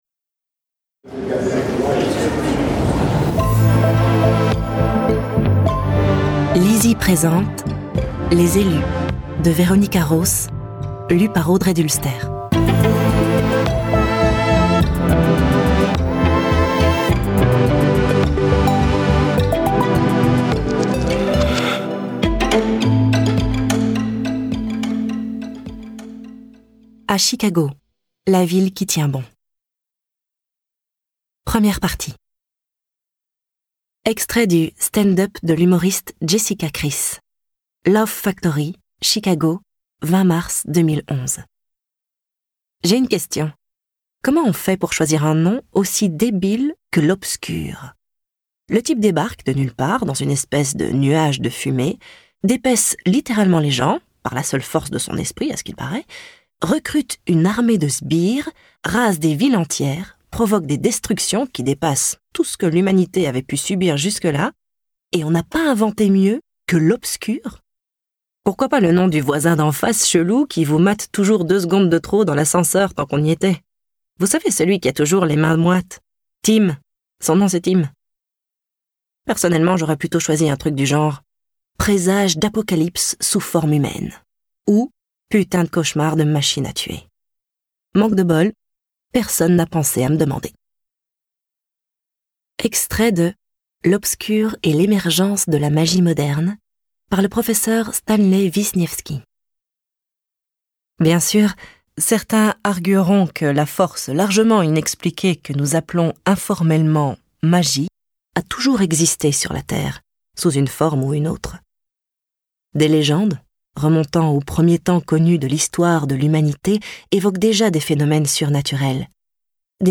je découvre un extrait - Les Elus - Tome 1 de Veronica Roth